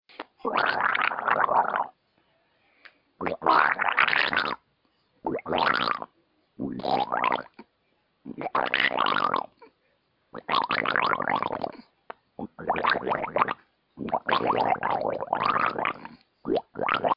Download Free Slime Sound Effects
Slime